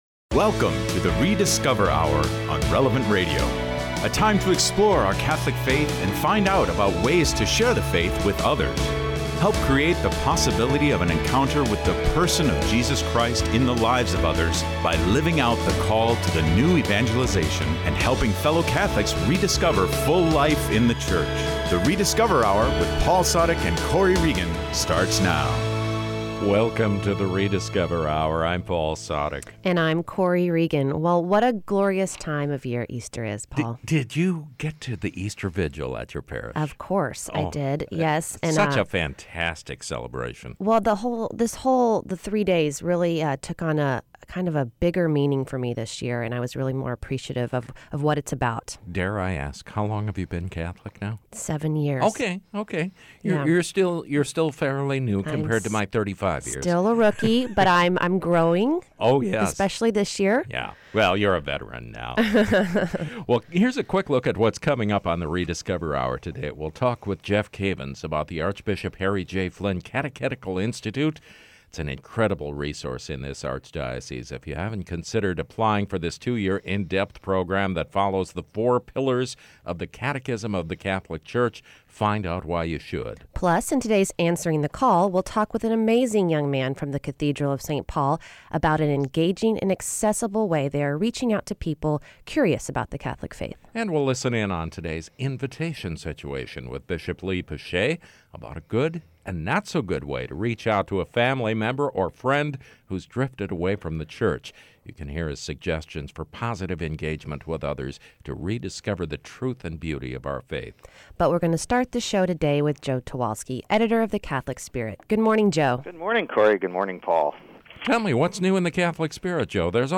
In Answering the Call, we’ll have an in-depth conversation about a new series at the Cathedral of Saint Paul for people who are interested in becoming Catholic…or rediscovering their Catholic faith.